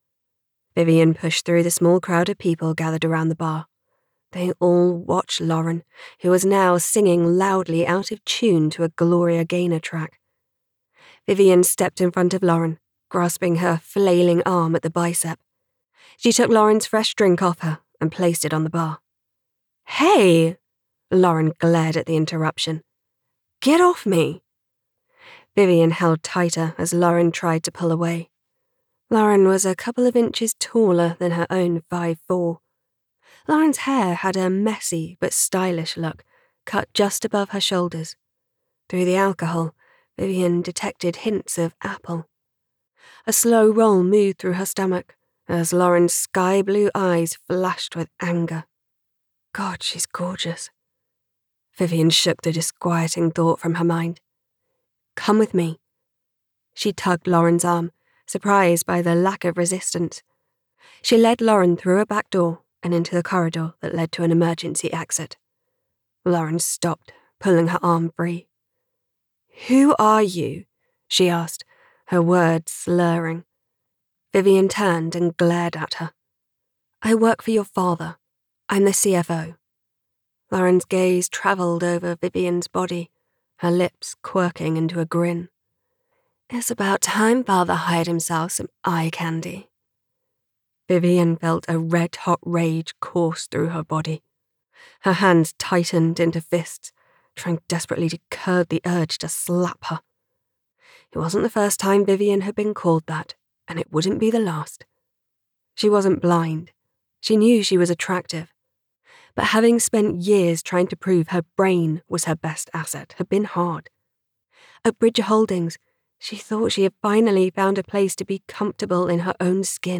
The Boss’s Daughter by Samantha Hicks [Audiobook]